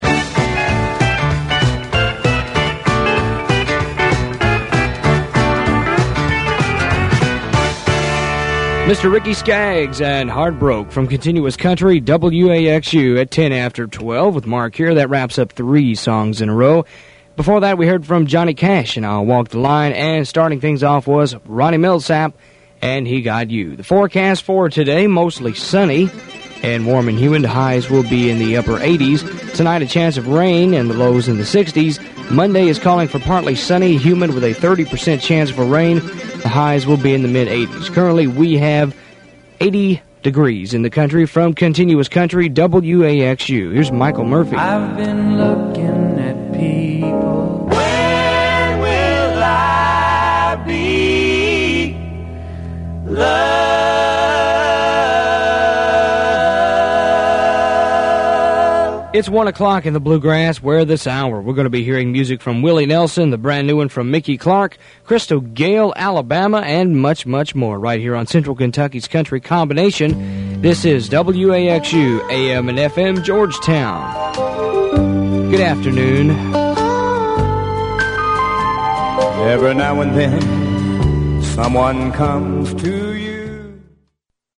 WAXU Airchecks